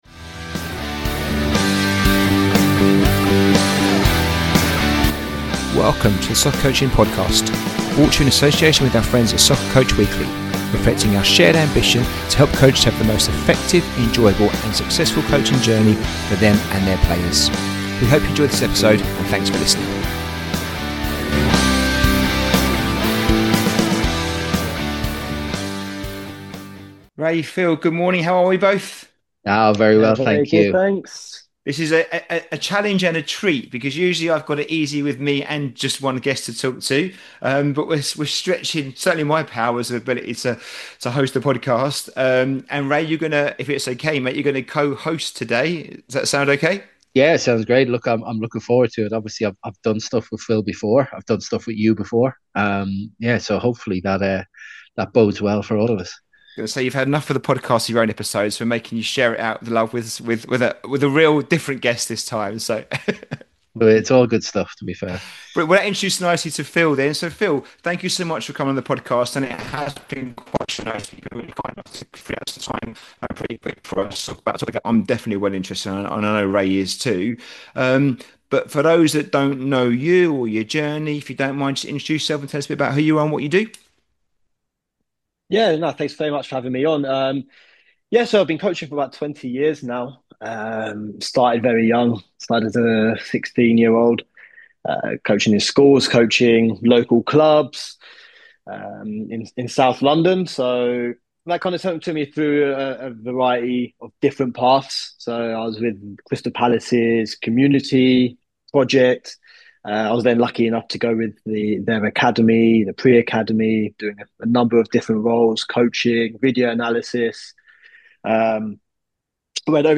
Episode 121 - Coaching Gen Z and Gen Alpha players, a conversation